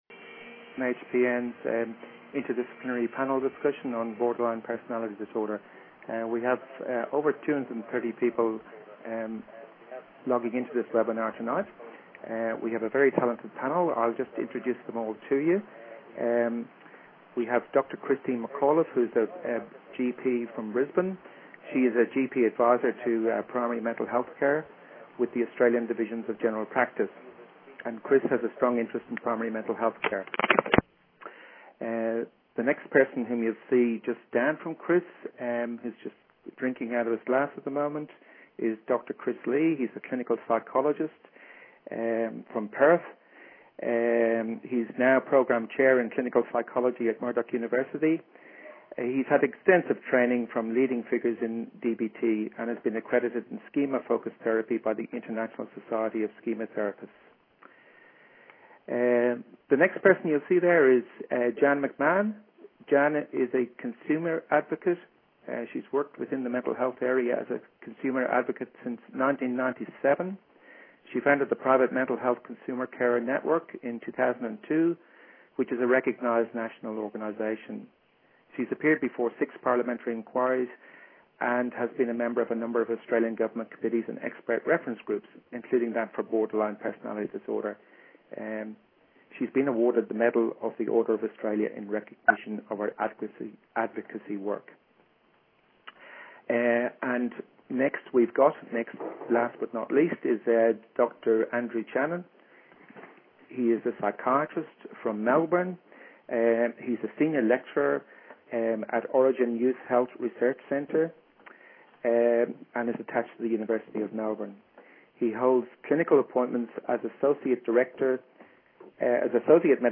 This webinar is the second of a two-part series, following the story of 17 year-old Tim's interactions with a variety of mental health professionals after seeing his GP. The interdisciplinary panel consider each profession’s perspective, as well as how they can work collaboratively to support Tim.